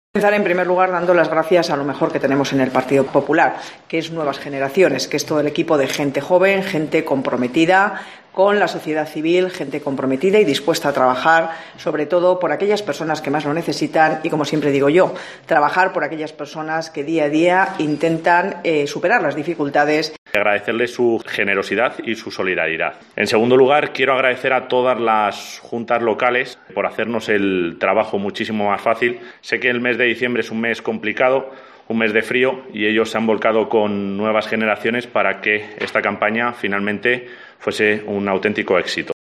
Así lo ha subrayado la presidenta del Partido Popular de Guadalajara, Ana Guarinos.